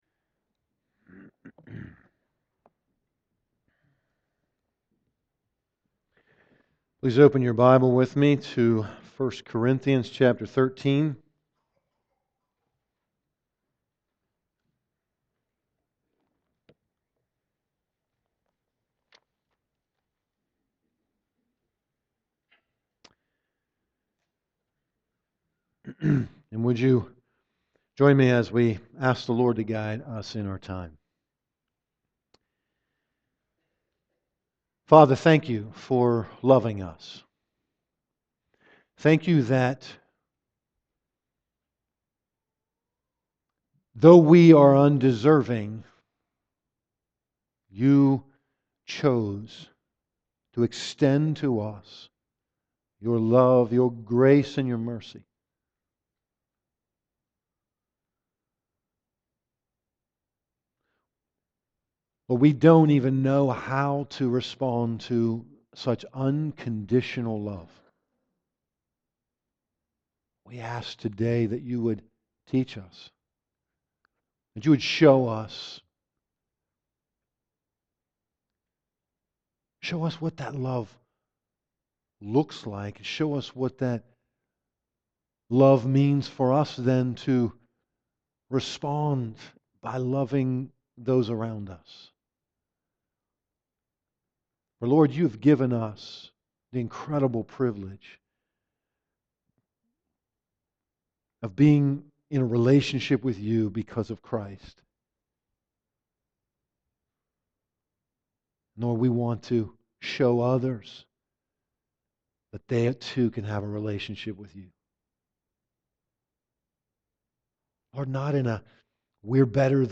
Galatians 4:21-5:1 Service Type: Morning Service